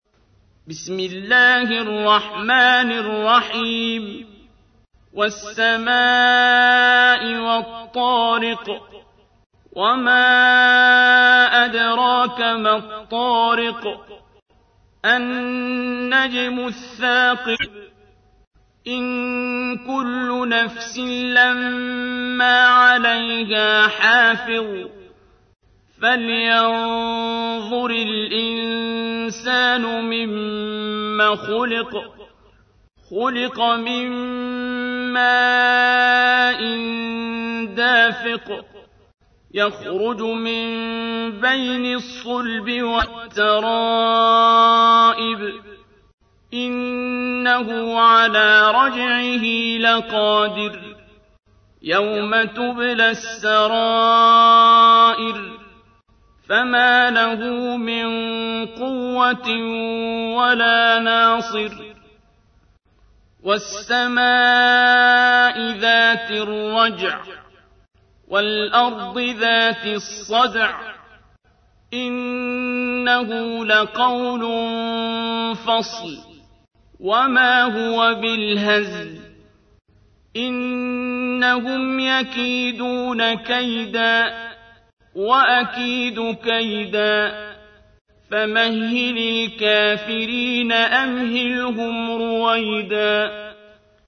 تحميل : 86. سورة الطارق / القارئ عبد الباسط عبد الصمد / القرآن الكريم / موقع يا حسين